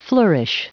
Prononciation du mot flourish en anglais (fichier audio)
Prononciation du mot : flourish